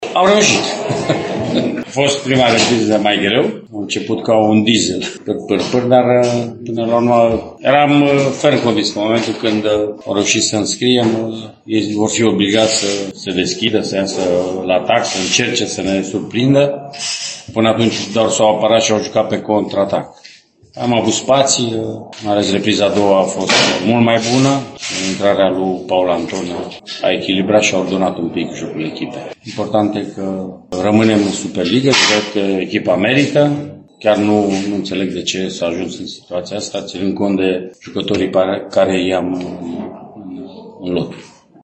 La declarațiile de după meci, Mircea Rednic și-a început discursul cu o exclamație, care spune multe despre încărcătura acestui final de sezon: